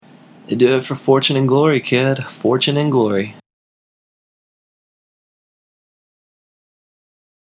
英語ネイティブによる発音は以下のリンクをクリックしてください。